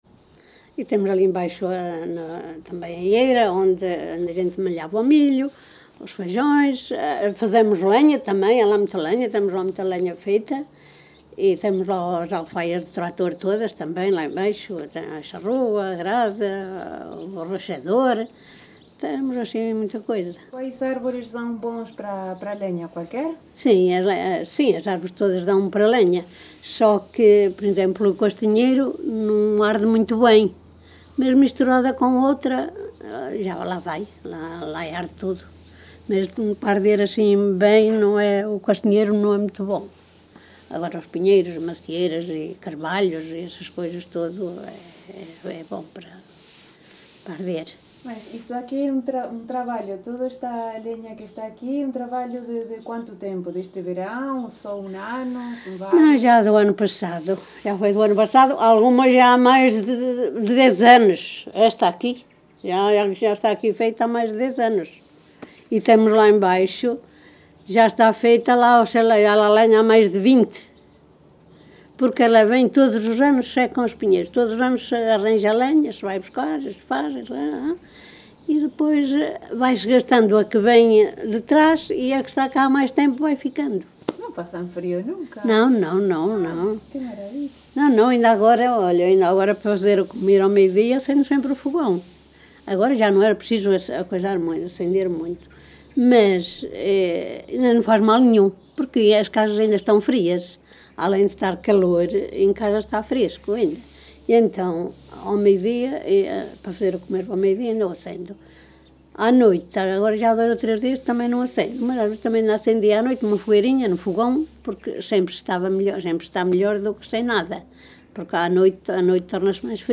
Várzea de Calde, primavera de 2019.
Tipo de Prática: Inquérito Oral